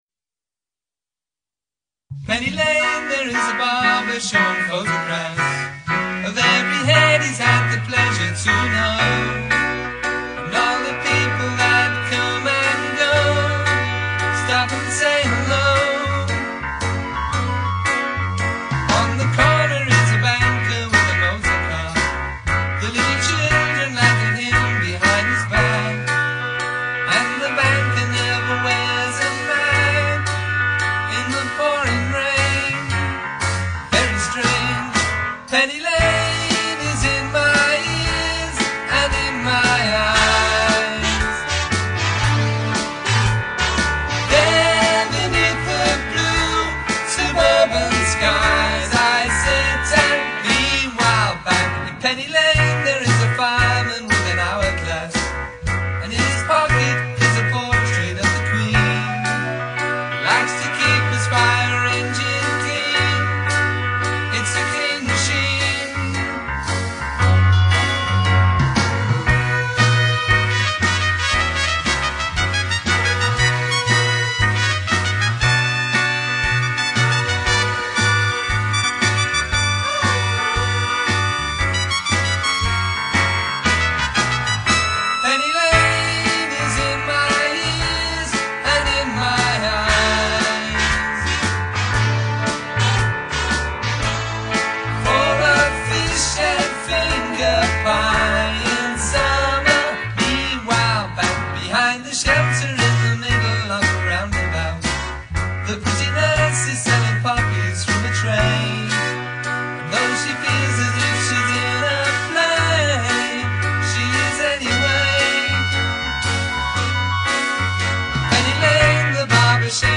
Live vocals, Guitars, Bass a